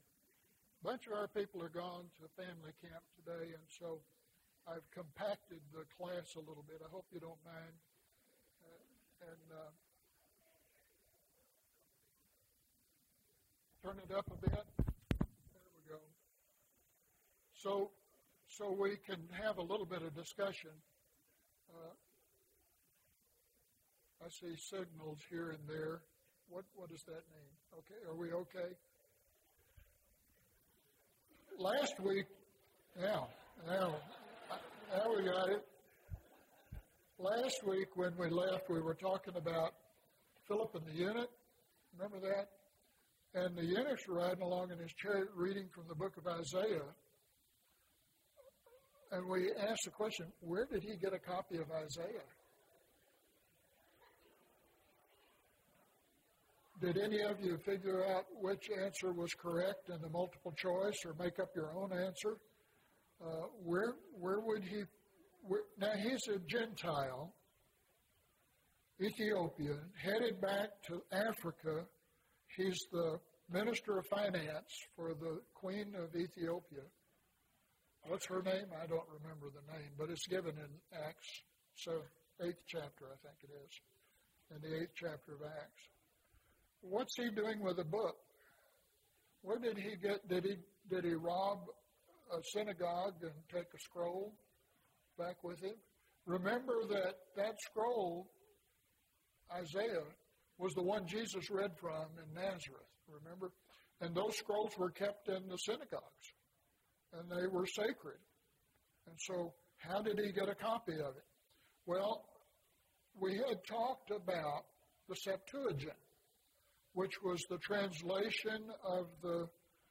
The Invention of Books: The Codex (4 of 14) – Bible Lesson Recording